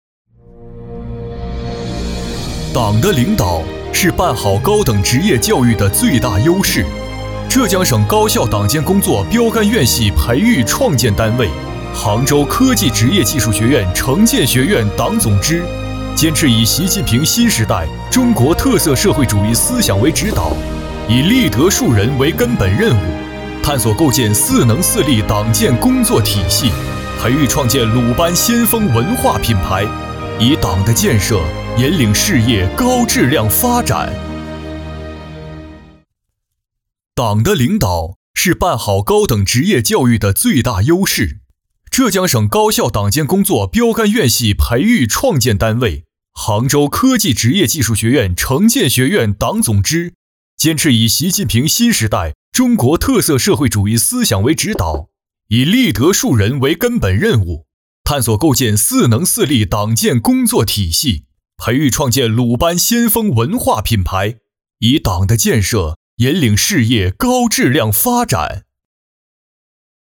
男28号
城建学院（大气沉稳）